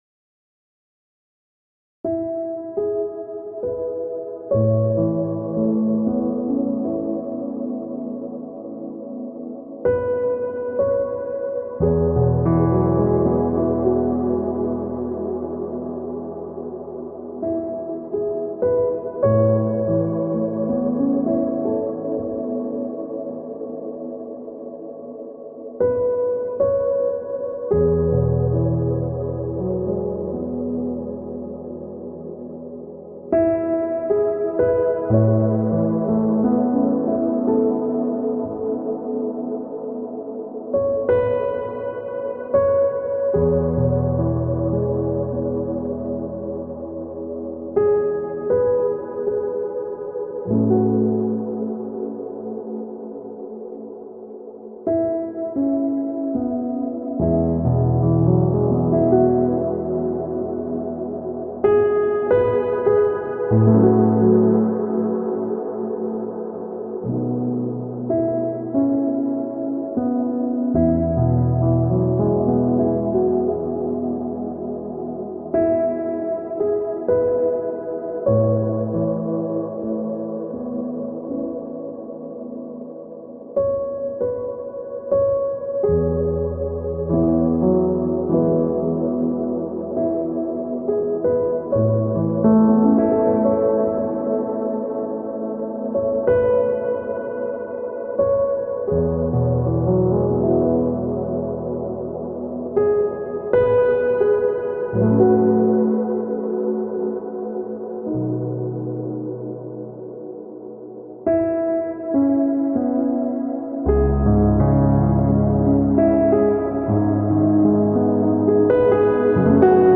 2 minutes of Asmr for sound effects free download
2 minutes of Asmr for deep sleep: Hand movements/ mouth sounds